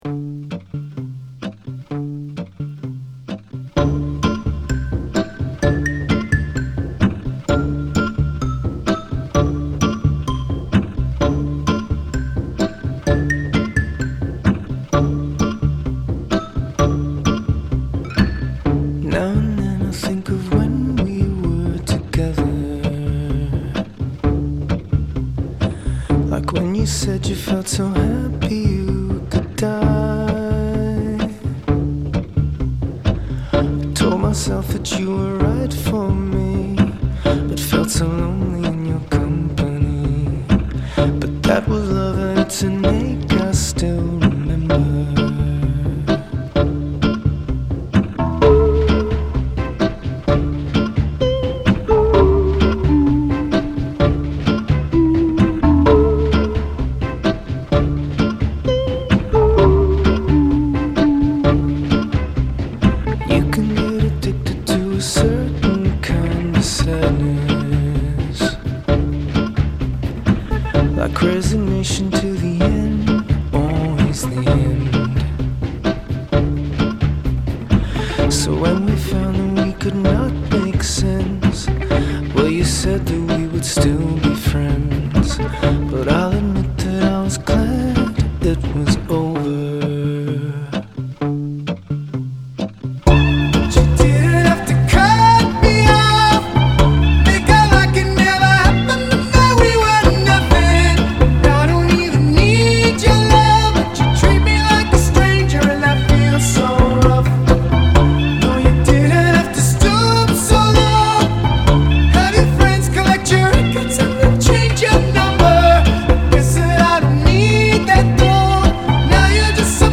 is a crushing breakup song.
He sings his sad sack tale of woe.